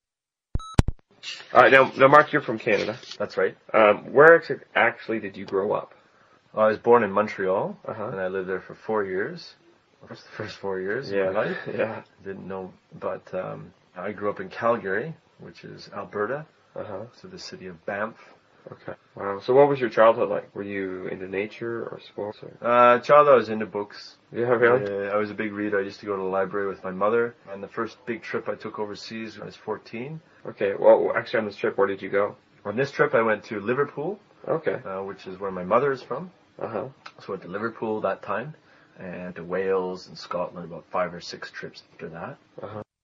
英语高级口语对话正常语速02：孩提时代(mp3)